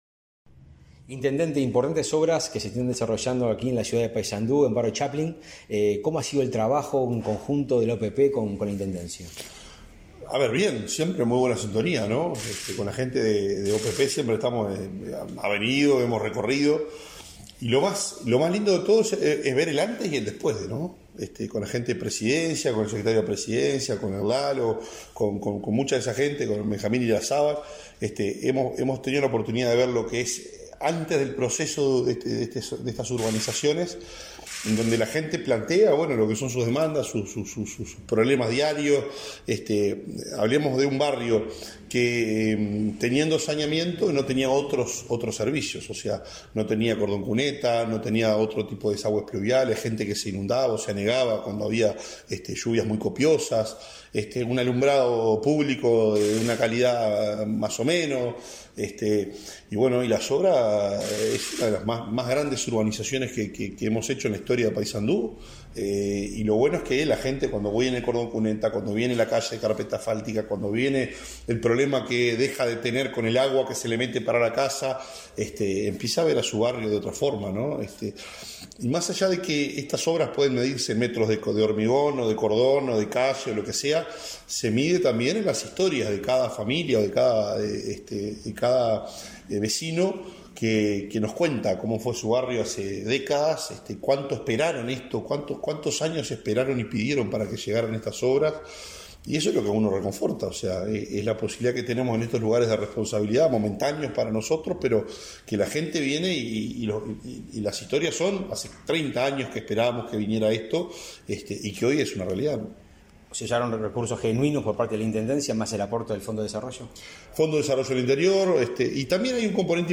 Entrevista al intendente de Paysandú, Nicolás Olivera
Entrevista al intendente de Paysandú, Nicolás Olivera 09/10/2023 Compartir Facebook X Copiar enlace WhatsApp LinkedIn La urbanización del barrio Chaplin, así como las mejoras viales en Piedras Coloradas y Lorenzo Geyres, conforman el plan de obras para el departamento de Paysandú, ejecutado con aportes de la Oficina de Planeamiento y Presupuesto (OPP). El intendente Nicolás Olivera destacó las obras realizadas, en declaraciones a Comunicación Presidencial.